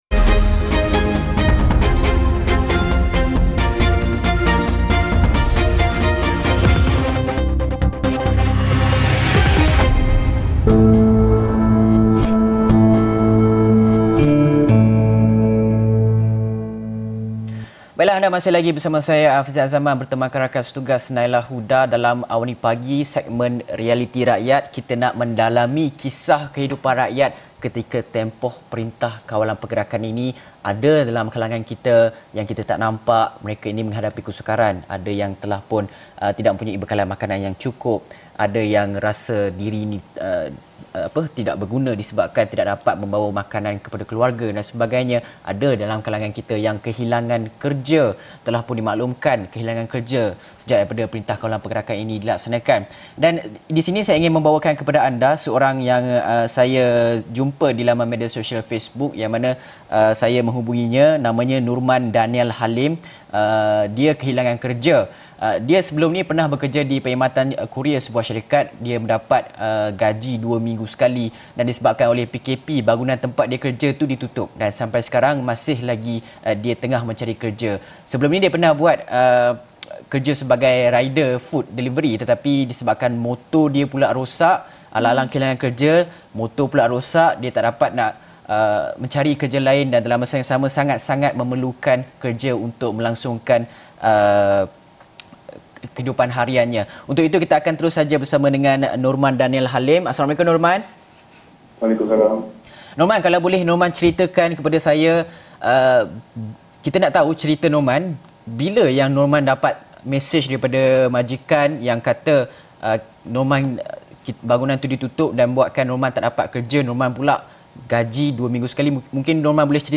Antara kebimbangan utama yang dihadapi adalah jumlah bekalan makanan yang perlu bertahan jika PKP berlarutan. Episod ini juga telah disiarkan secara langsung dalam program AWANI Pagi, jam 8:30 pagi.